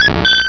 Cri de Mélodelfe dans Pokémon Rubis et Saphir.